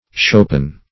Shopen \Sho"pen\